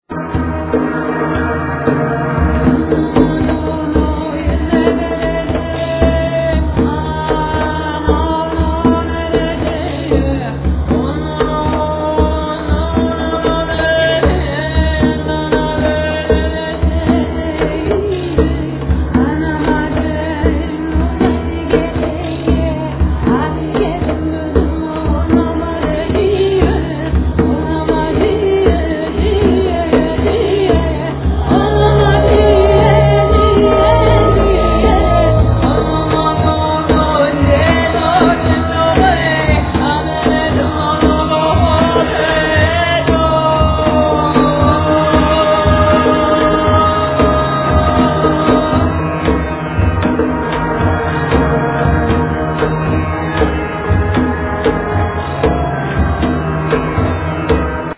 Vocals, Percussions
Programming, Guitars, Saz, Percussions
Programming, Keyboards, Santur, Percussions
Percussions, Tapan, Gaidunitza, Daf
Renaissance lute, Block flute